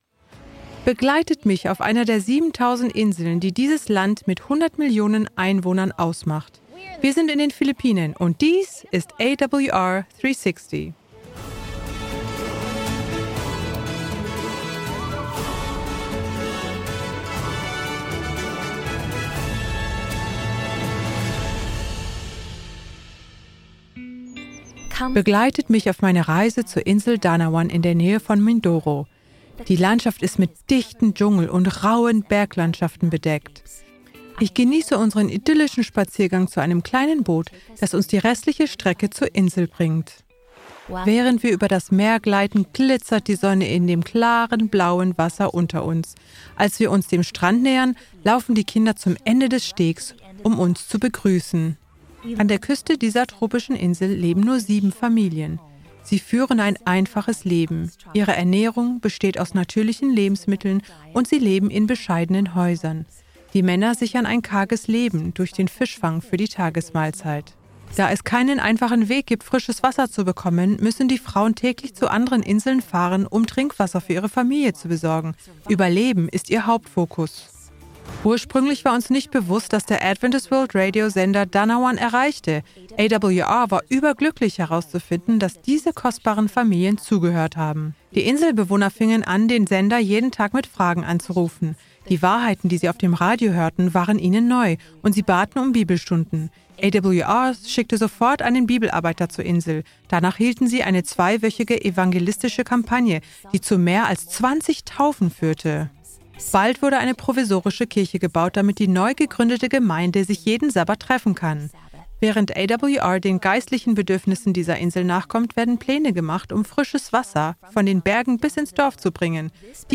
Kategorie Zeugnis